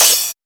percussion 22.wav